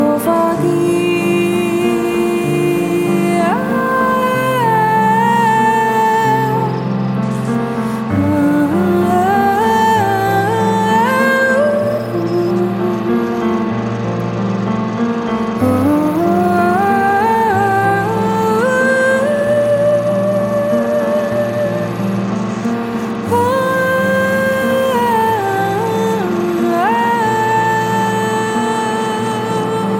'nordic noir'
voz, piano e mellotron, elementos sempre reduzidos, esparsos
Despojado e simples